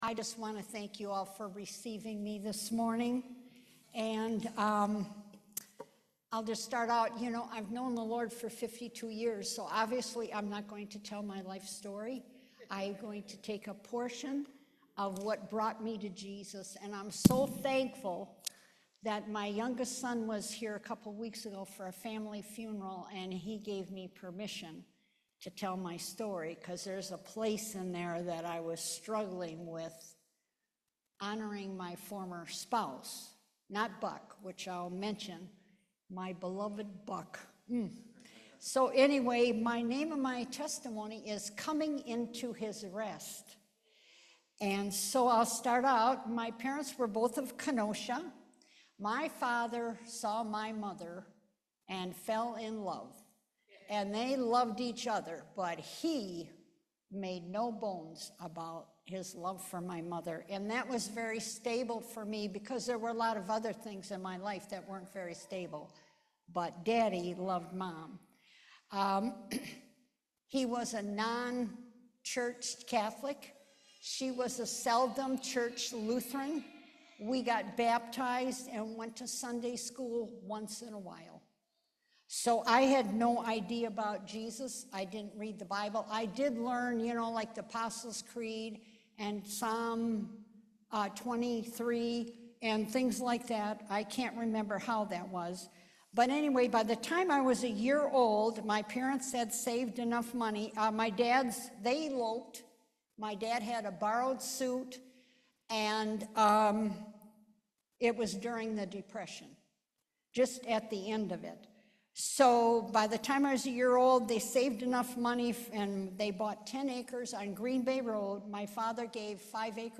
Series: Testimony
2 Timothy 1:12 Service Type: Main Service I know my God is faithful.